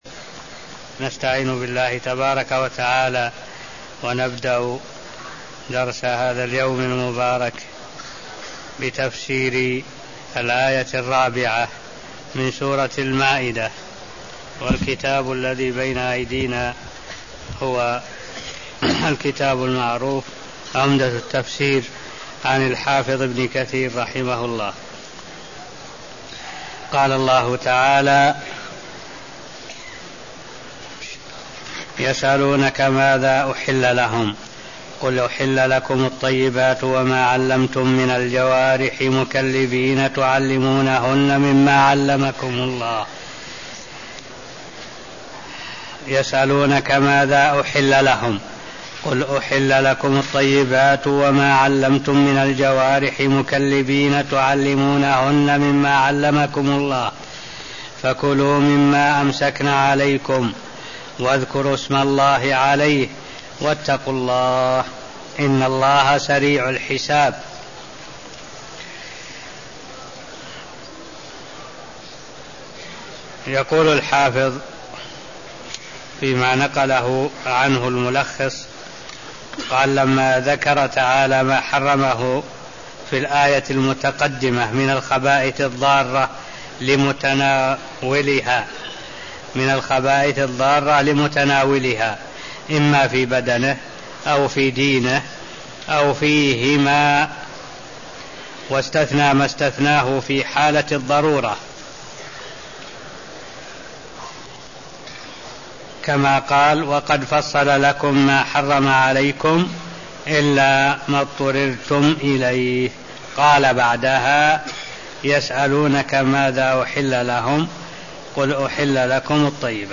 المكان: المسجد النبوي الشيخ: معالي الشيخ الدكتور صالح بن عبد الله العبود معالي الشيخ الدكتور صالح بن عبد الله العبود تفسير سورة المائدة آية 4 ومابعدها (0226)